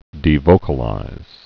(dē-vōkə-līz)